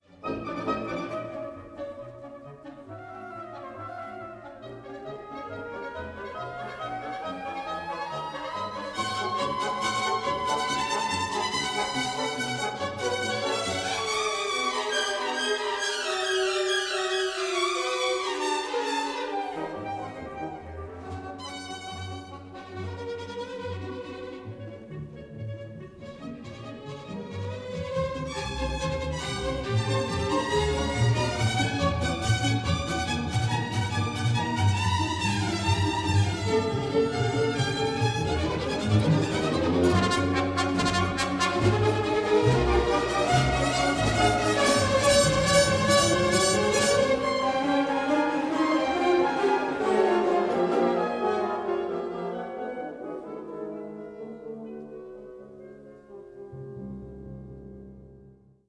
in F minor for orchestra